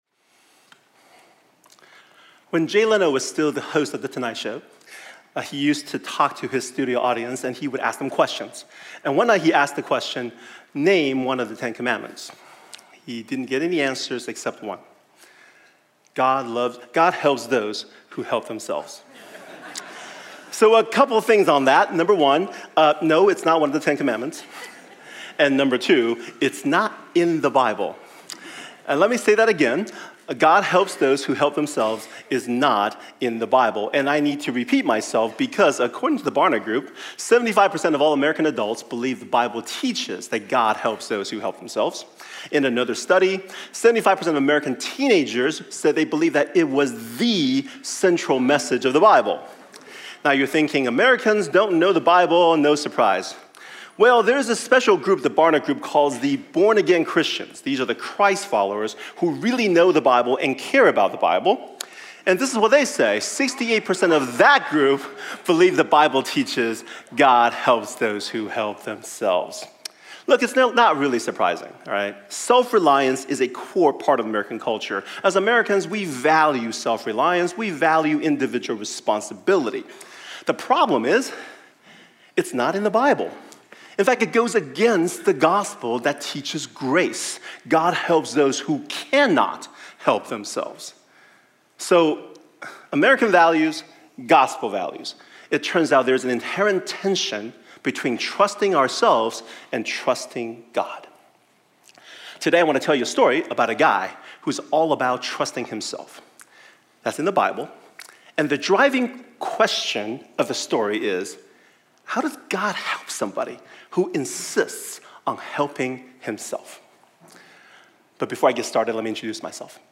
A message from the series "This is Us."